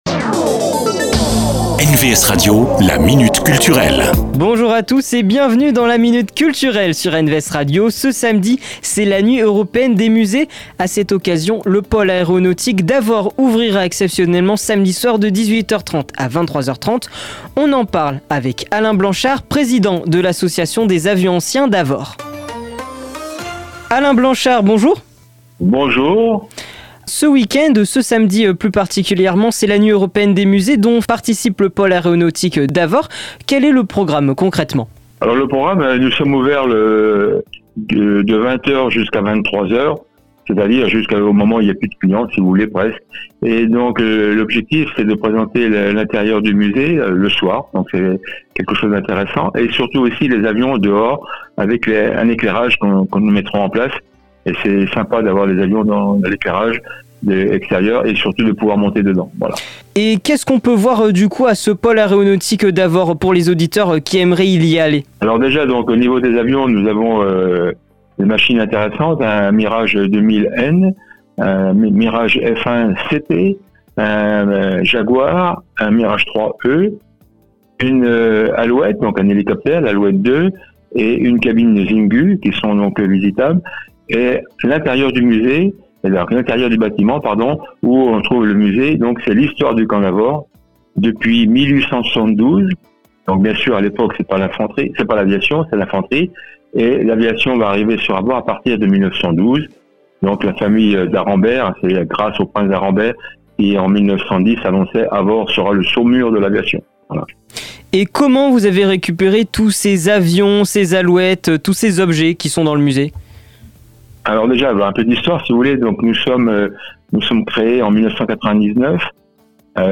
La Minute Culture, rencontre avec les acteurs culturels de votre territoire.